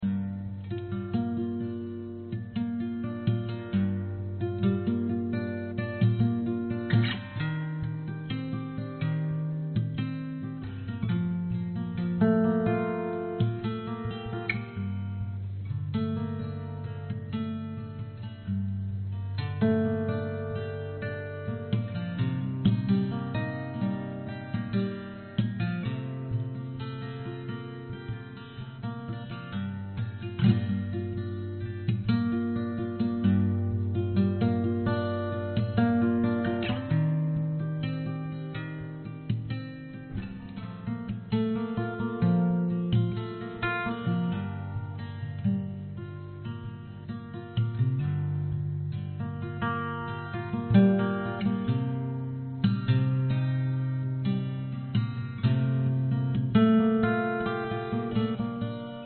描述：用两个话筒录制了这个原声吉他的主题：一个是弹奏的声音，一个是主体的声音。我加入了iZotope Alloy和Ozone以及Sonitus混响。
Tag: 原声 寒冷 吉他